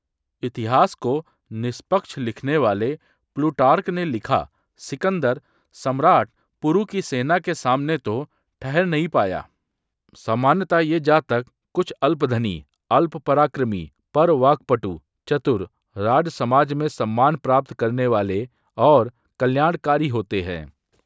TTS_multilingual_audios